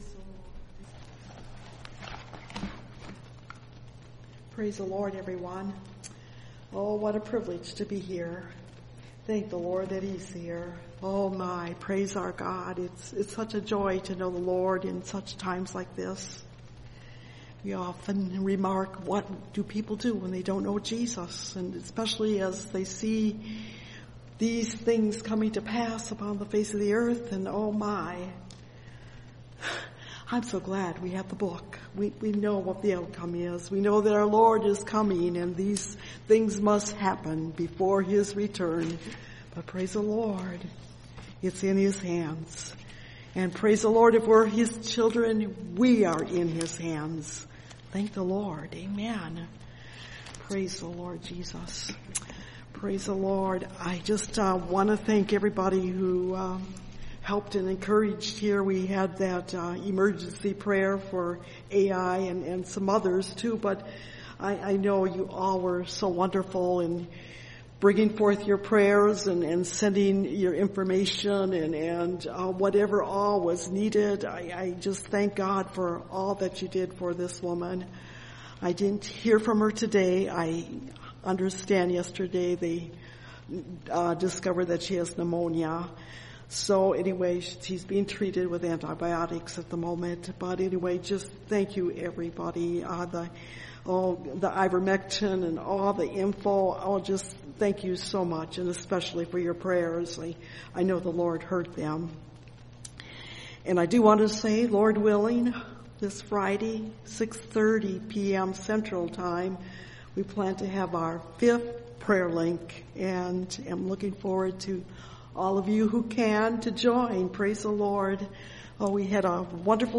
Treasures In Heaven (Message Audio) – Last Trumpet Ministries – Truth Tabernacle – Sermon Library